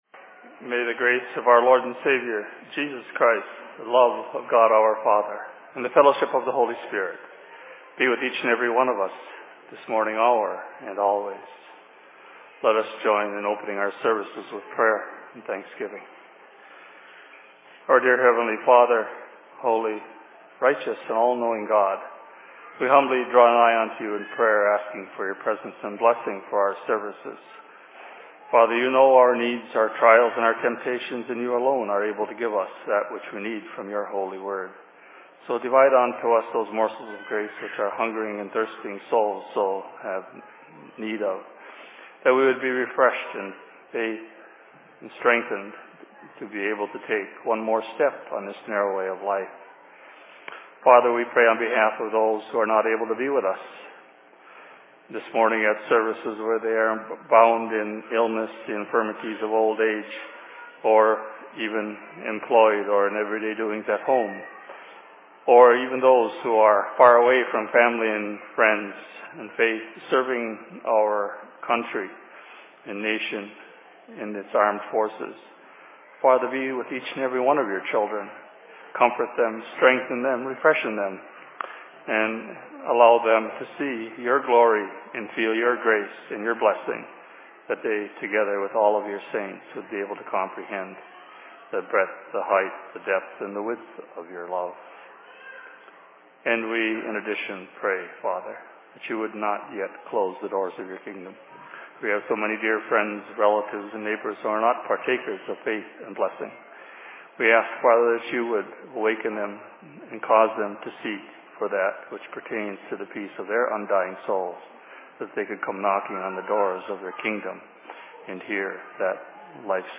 Sermon on Minneapolis 30.01.2011